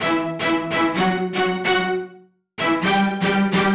Index of /musicradar/uk-garage-samples/128bpm Lines n Loops/Synths
GA_StaccStr128E-03.wav